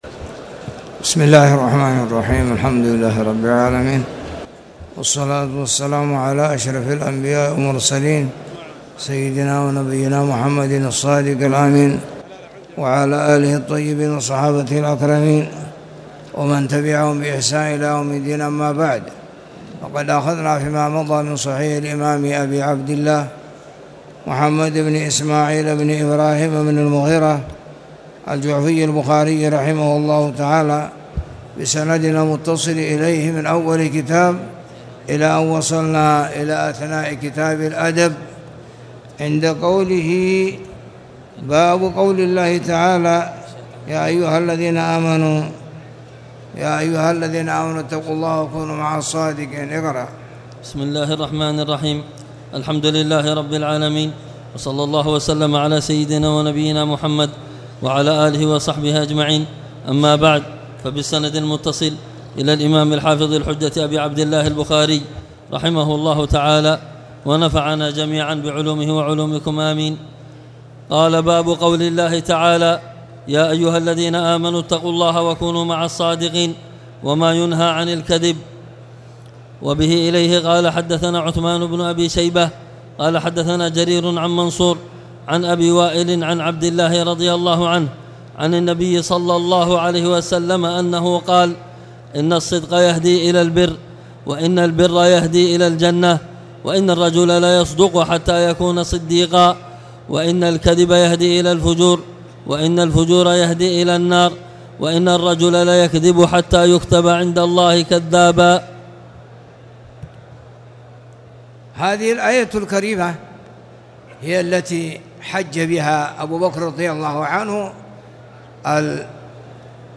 تاريخ النشر ١٣ جمادى الآخرة ١٤٣٨ هـ المكان: المسجد الحرام الشيخ